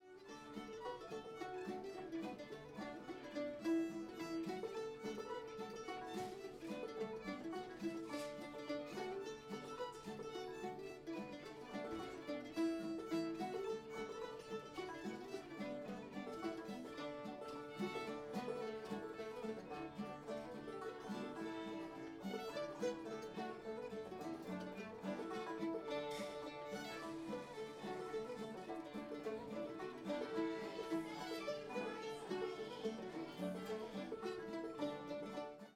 belle of lexington [D]